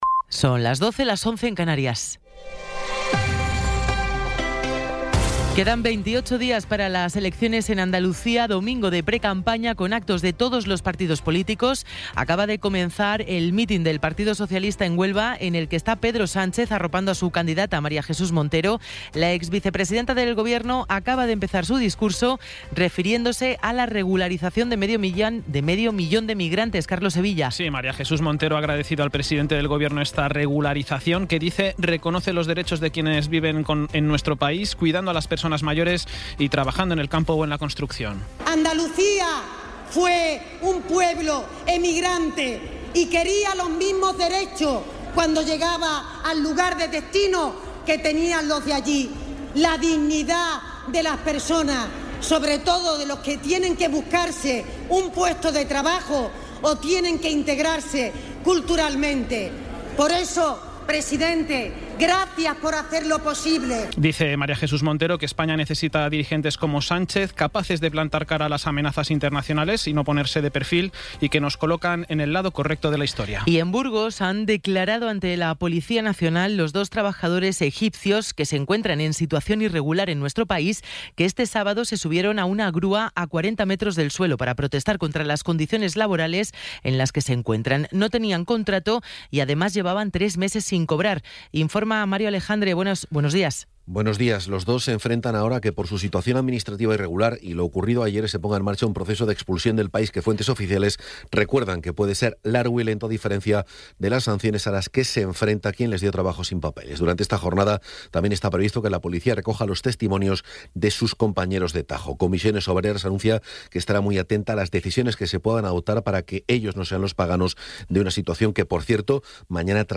Resumen informativo con las noticias más destacadas del 19 de abril de 2026 a las doce.